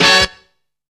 CAT HIT.wav